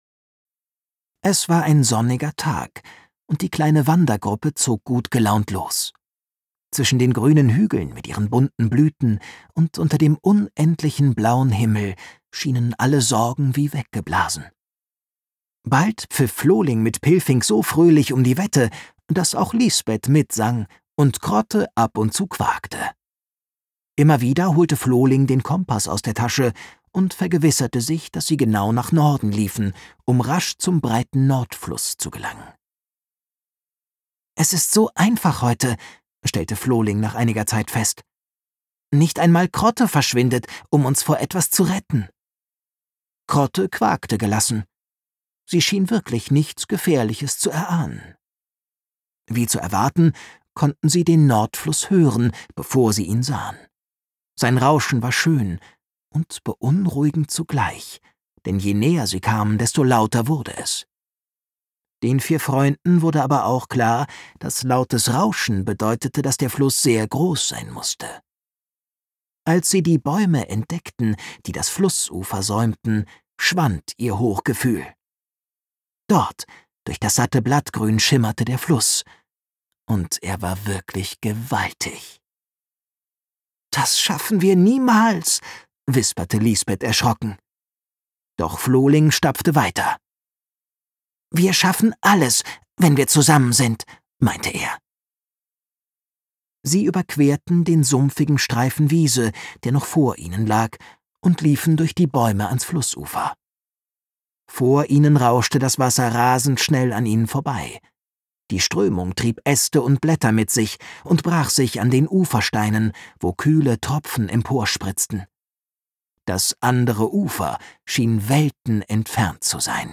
Hörbuch: Der kleine Flohling 1.